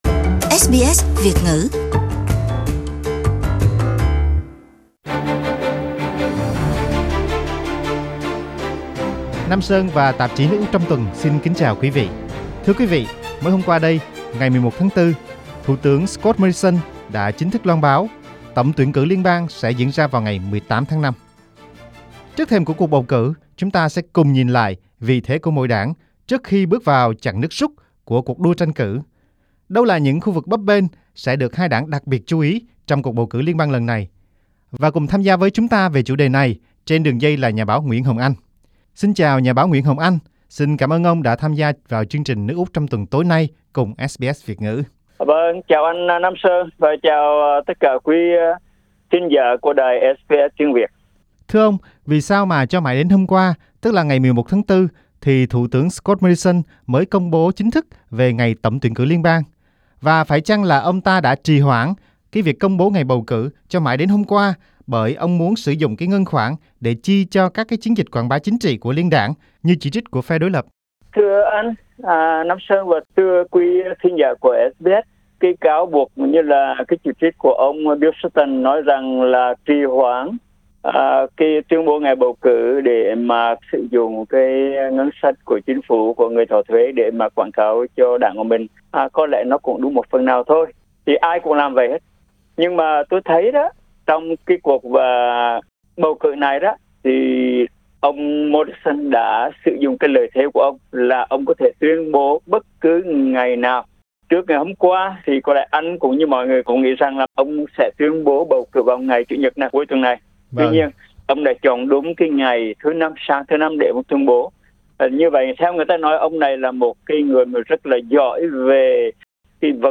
Trong cuộc phỏng vấn với SBS Việt ngữ